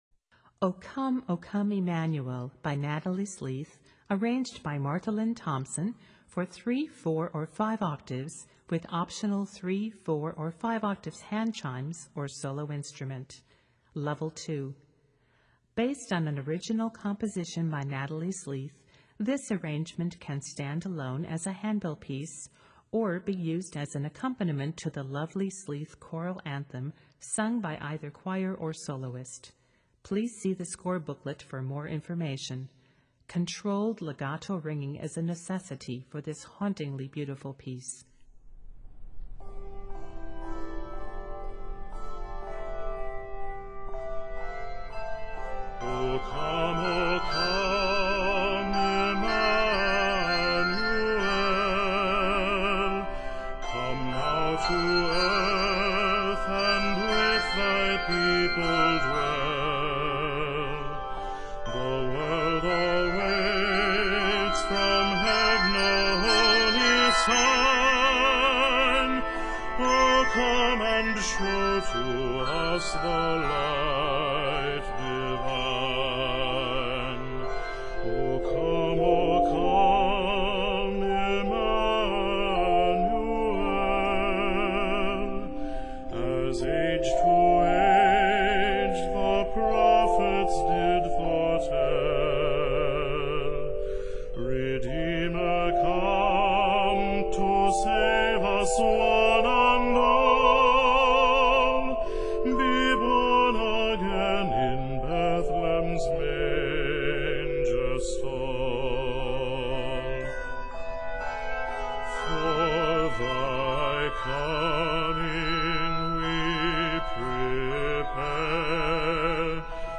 handbell piece